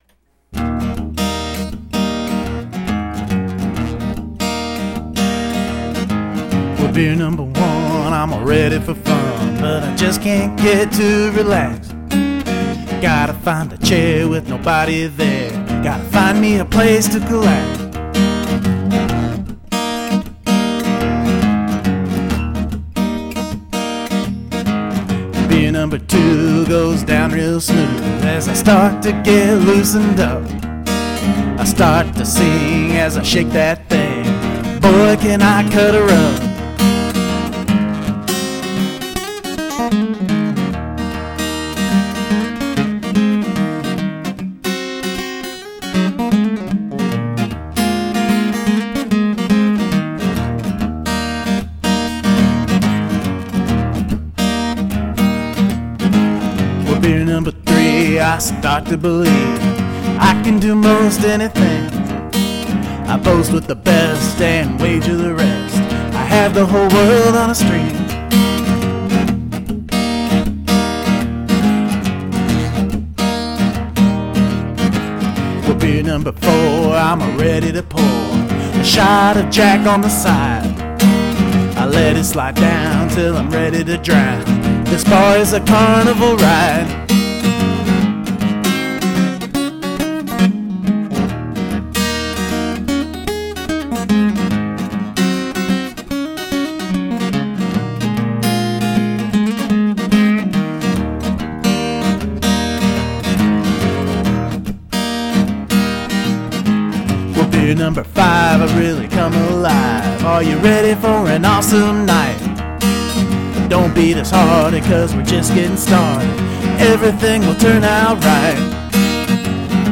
Then I just put the simple common chord progression to it. This is a one take acoustic/vox all recorded at once type of demo.
Robert Johnson look out - now that's a drinking song!
Great concept,and I really love the guitar work.